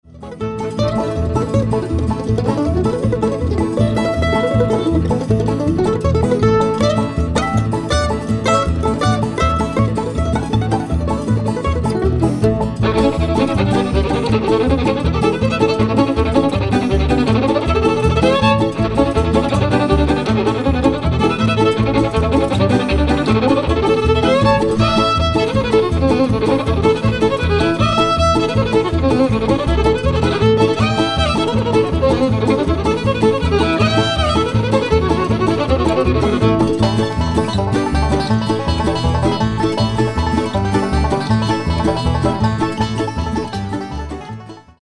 An instrumental album
mostly from the Bluegrass and Old-Time repertoire